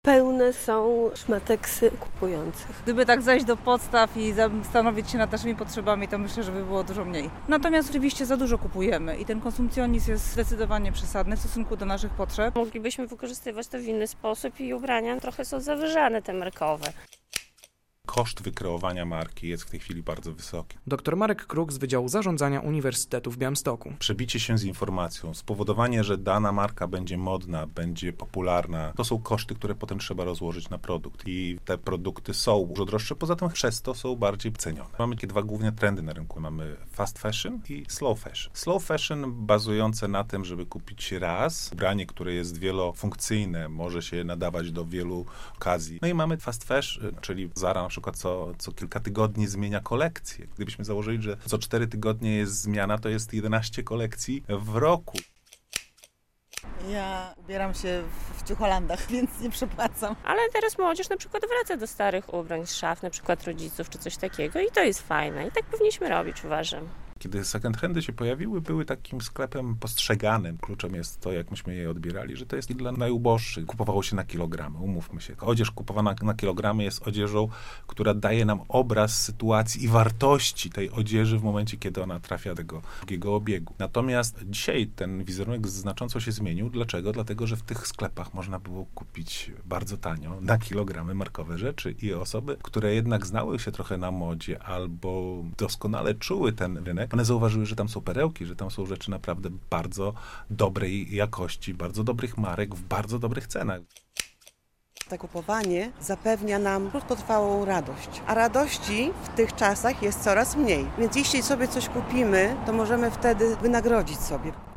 Czy kupujemy za dużo ubrań - relacja
Niektórzy pytani przez nas białostoczanie przyznają, że celowo nie kupują dużo nowych ubrań, a chodzą do sklepów z odzieżą używaną.